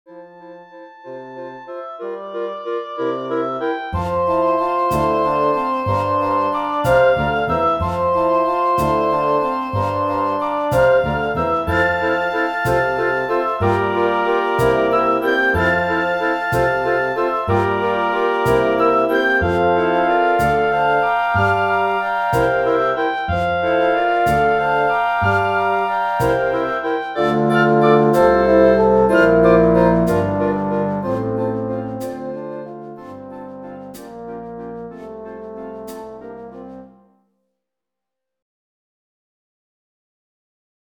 for Wind Ensemble